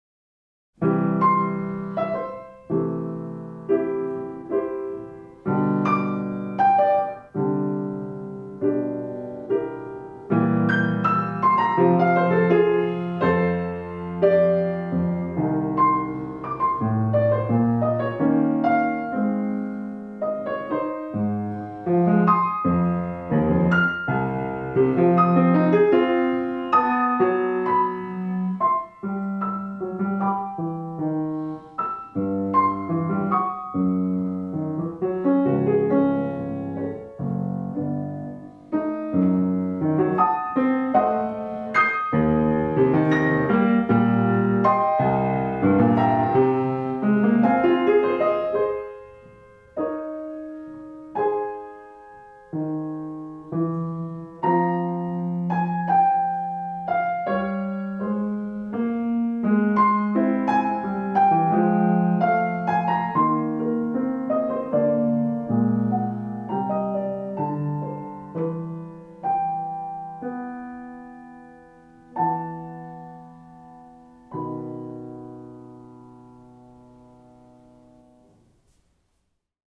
großmütig